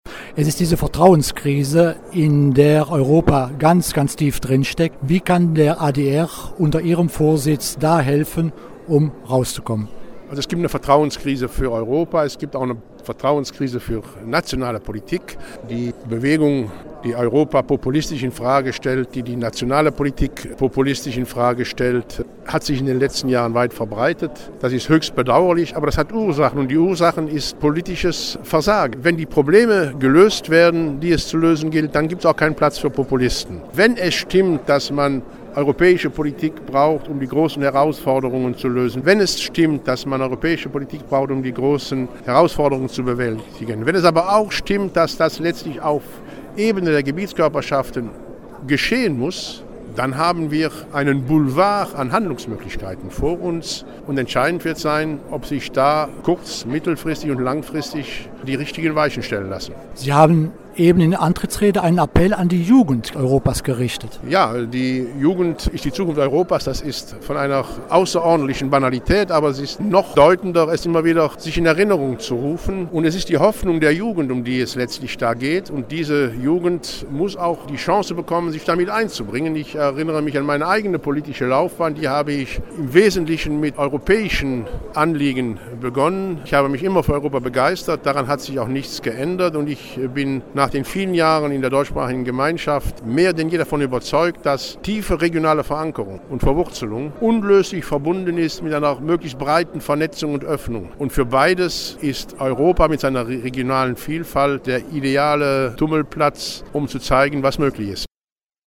hat sich mit Karl-Heinz Lambertz über sein neues Amt unterhalten: